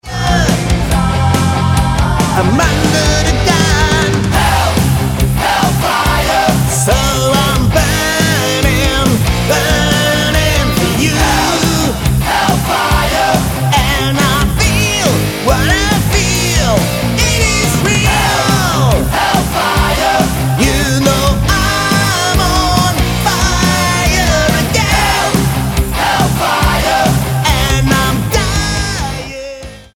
Lead Vocals
Guitar
Drums